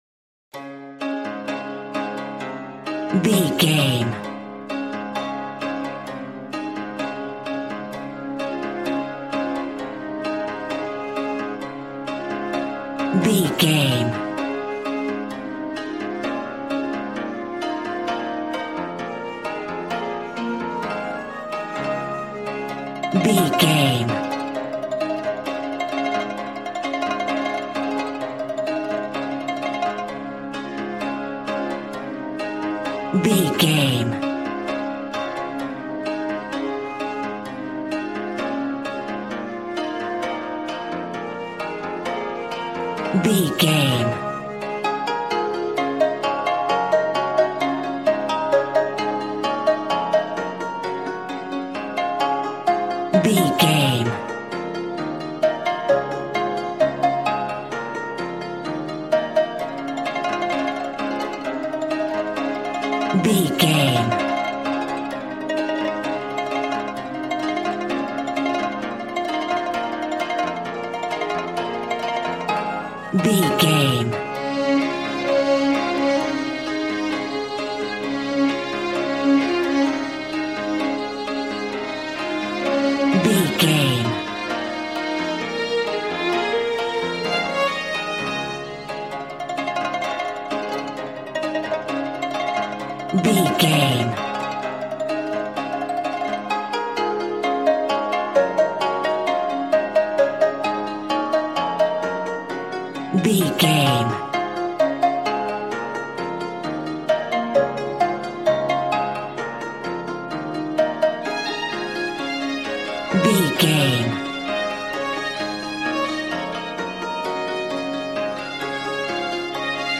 Ionian/Major
A♭
smooth
conga
drums